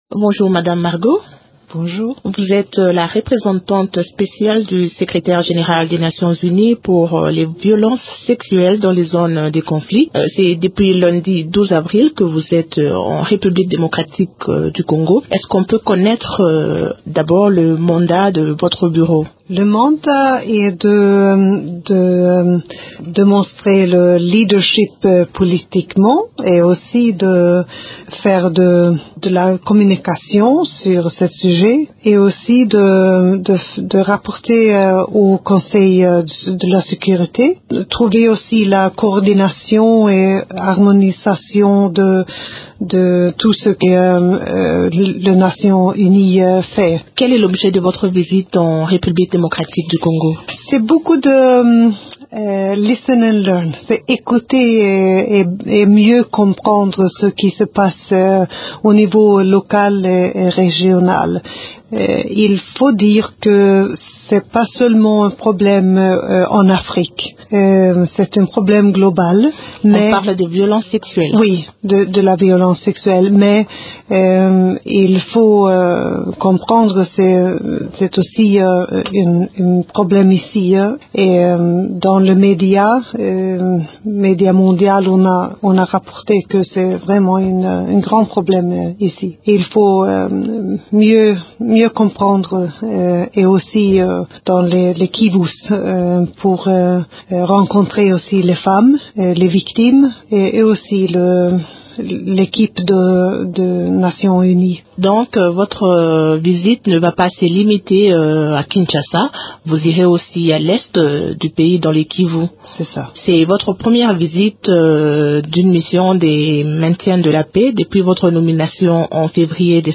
s’est entretenue avec elle.